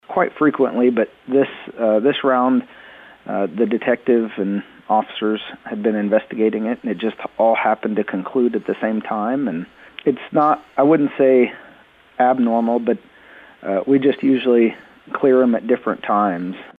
Chief Maples says the Detectives and Officers are investigating these types of cases regularly, working with the children’s division and other agencies.  He says these investigations happened to conclude about the same time.